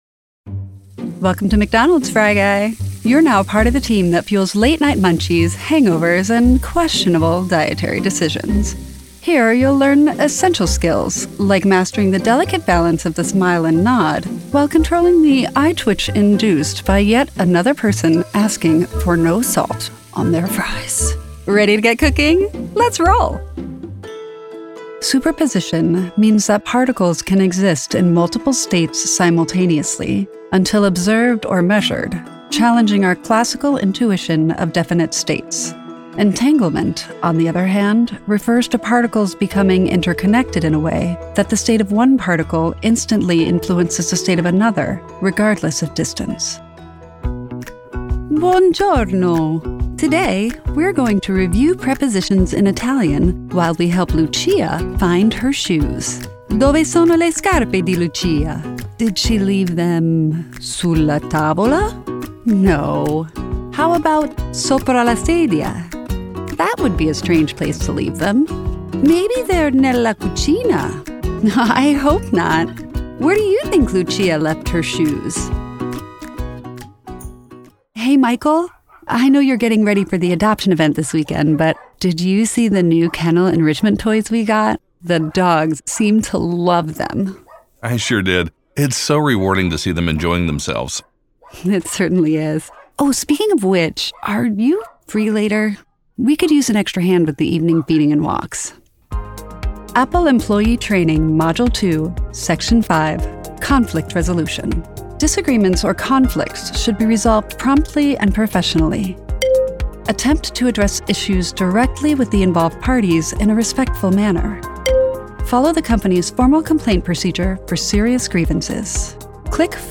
Natürlich, Cool, Zugänglich, Vielseitig, Warm
E-learning